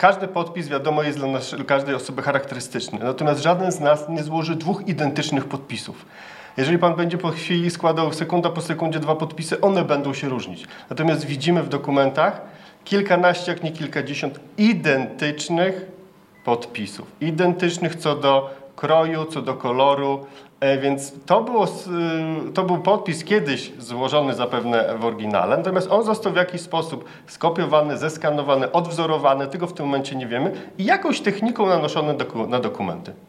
Szczegóły przedstawili w czwartek (10.01) na konferencji prasowej Mirosław Karolczuk
Mirosław-Karolczuk-1.mp3